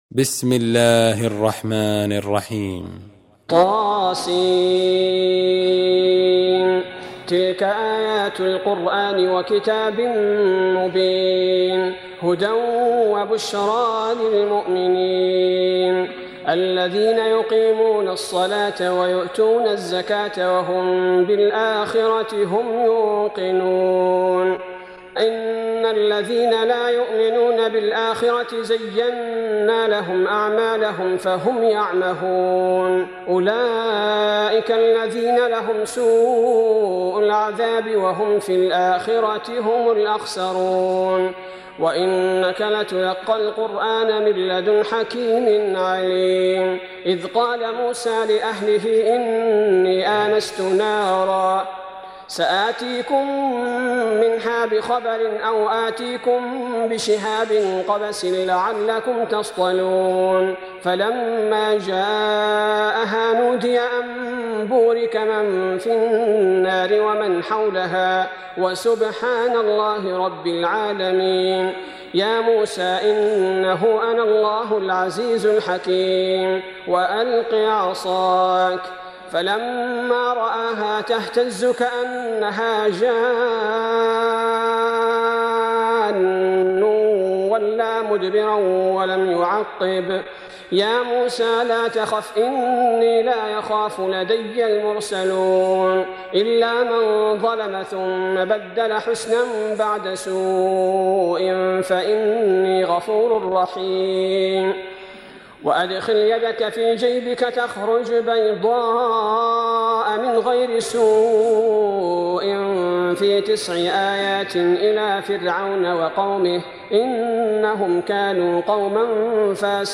Surah Repeating تكرار السورة Download Surah حمّل السورة Reciting Murattalah Audio for 27. Surah An-Naml سورة النّمل N.B *Surah Includes Al-Basmalah Reciters Sequents تتابع التلاوات Reciters Repeats تكرار التلاوات